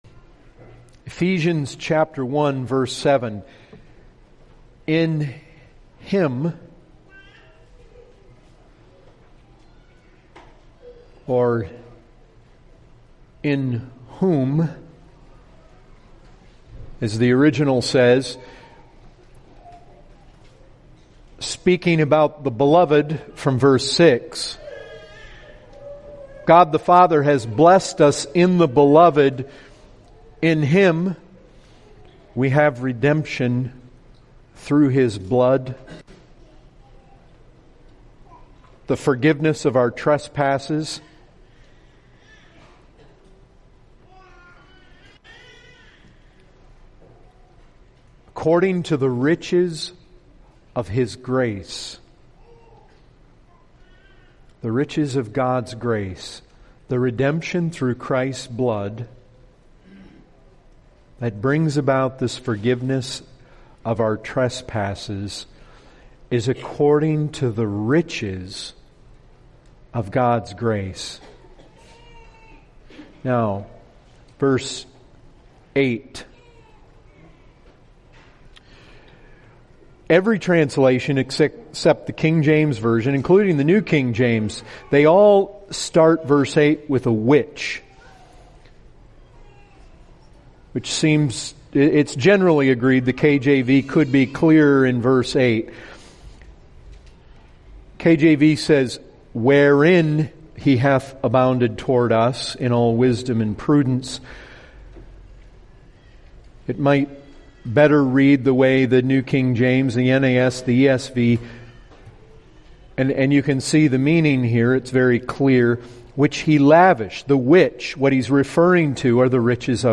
58:40 - Full Sermon - When God saves someone there is a revelation of the mystery of God's will that is in Christ.